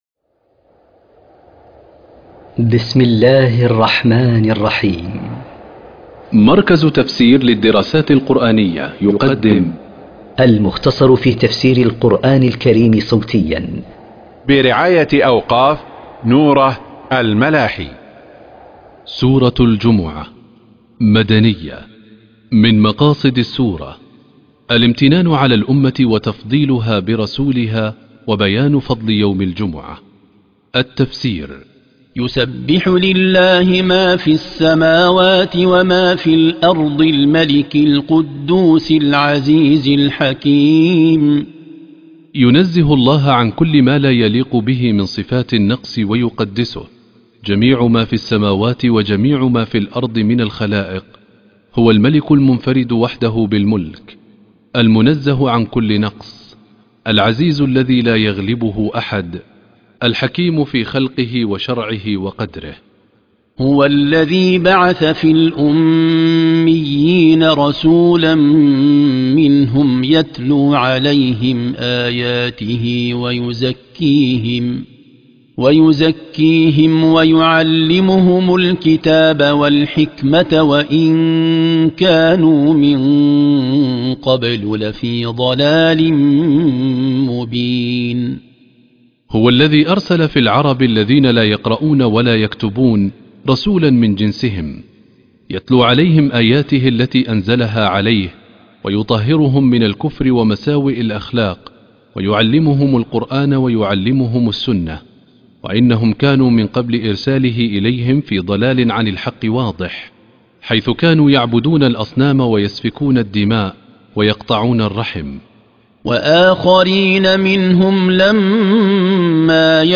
قراءة تفسير سورة الجمعة من كتاب المختصر